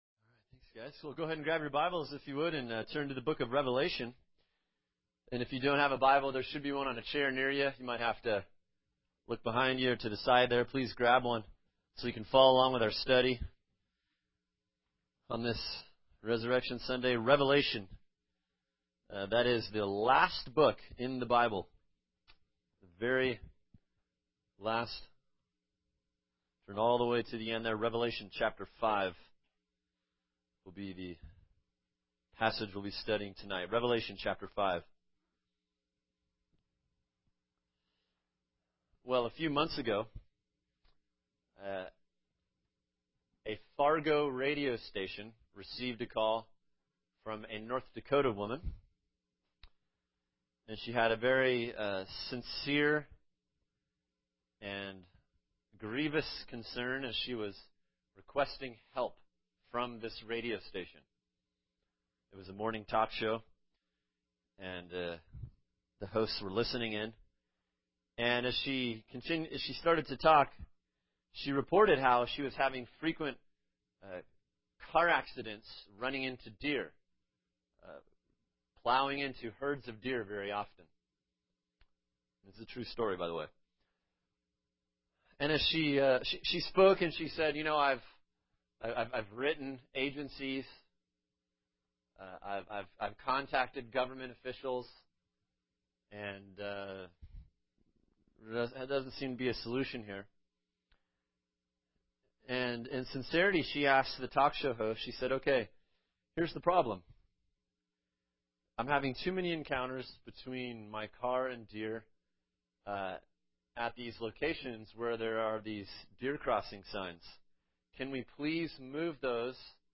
[sermon] Revelation 5 “Risen For Worship” | Cornerstone Church - Jackson Hole